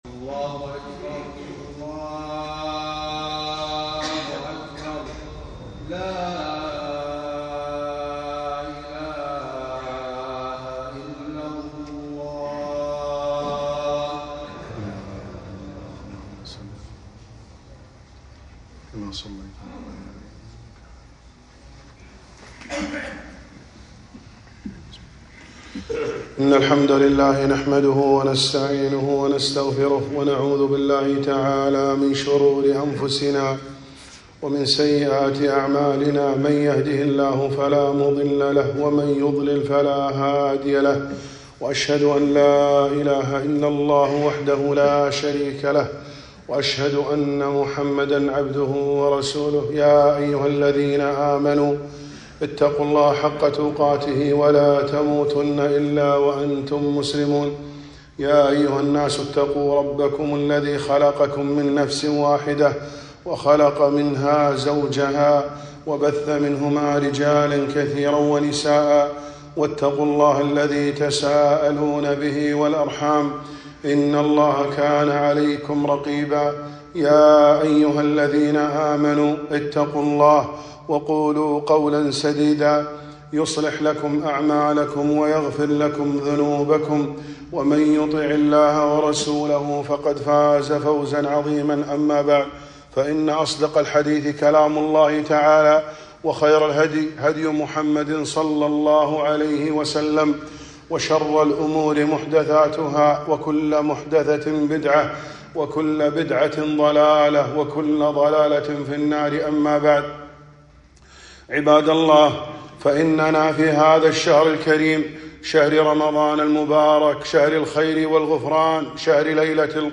خطبة - رمضان شهر القرآن